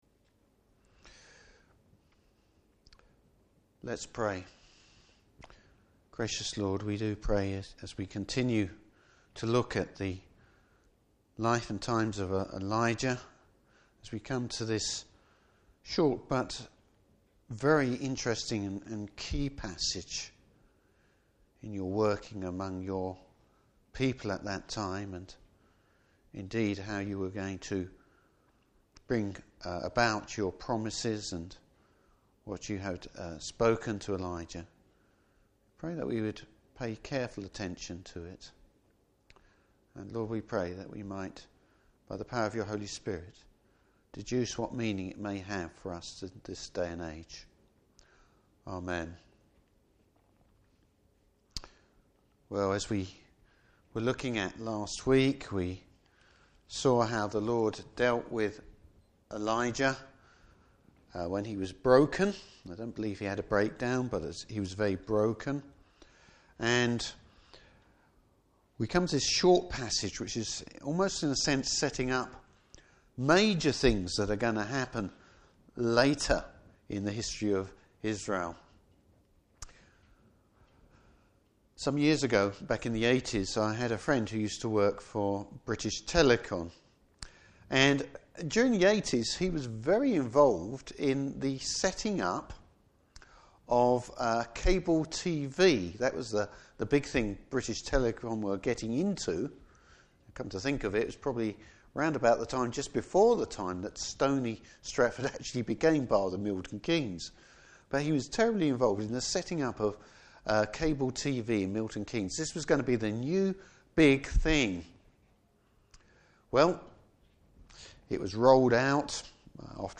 Service Type: Evening Service Bible Text: 1 Kings 19:19-21.